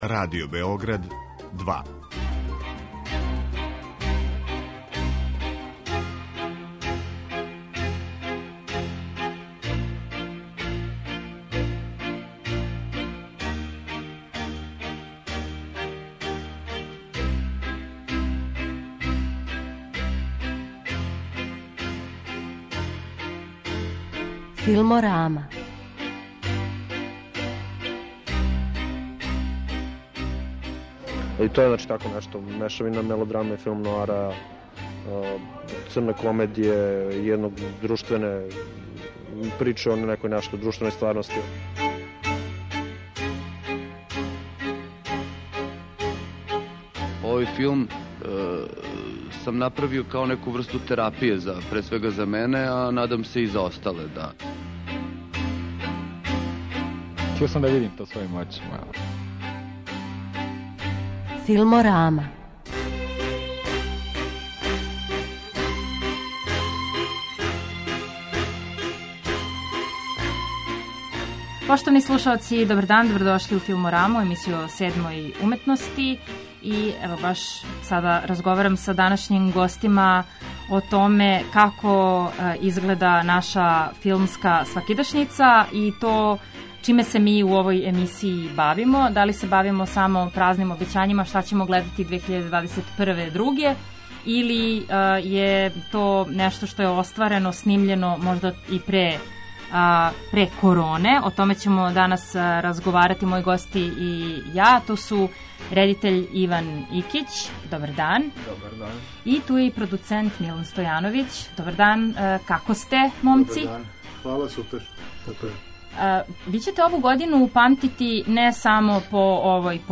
Емисија о седмој уметности